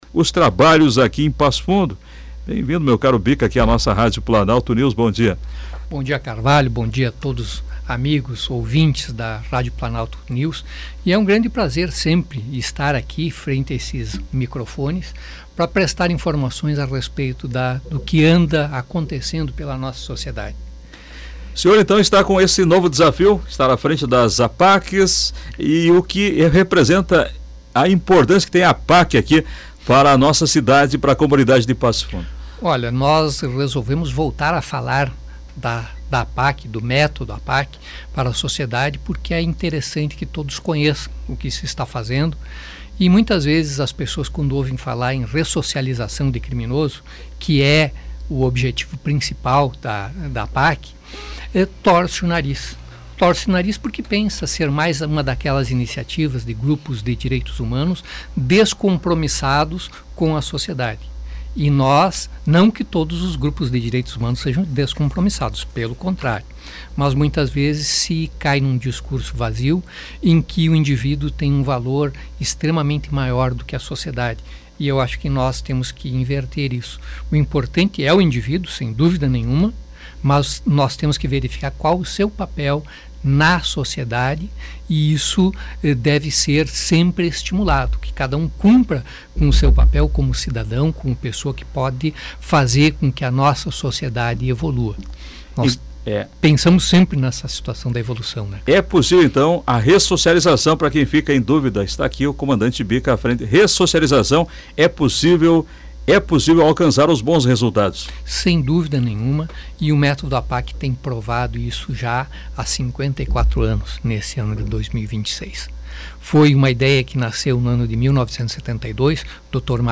Ele esteve no programa Comando Popular, da Rádio Planalto News (92.1)